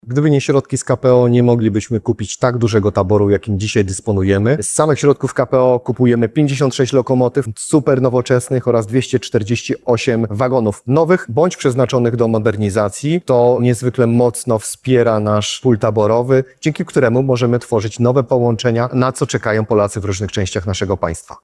– mówi Dariusz Klimczak, Minister Infrastruktury.